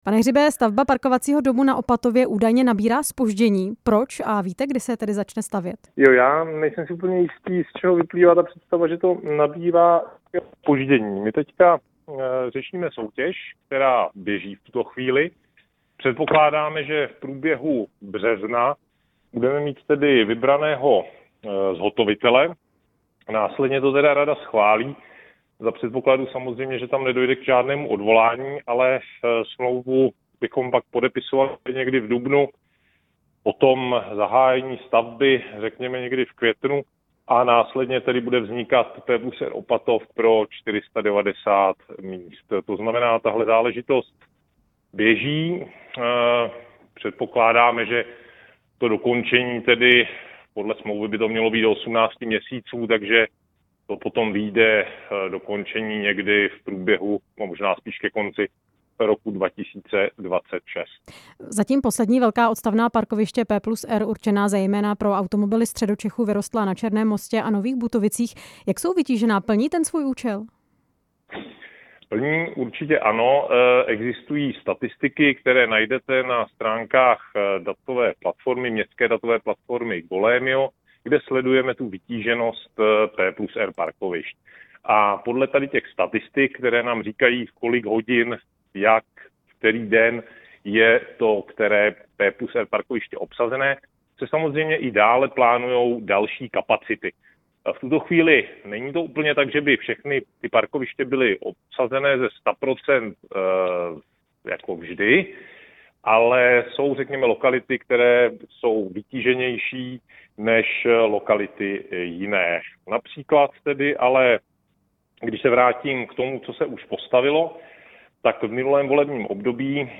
Na podrobnosti jsme se ve vysílání Radia Prostor ptali Zdeňka Hřiba, náměstka primátora hlavního města Prahy pro dopravu a předsedy Pirátské strany.
Rozhovor s předsedou Pirátů a pražským náměstkem pro dopravu Zdeňkem Hřibem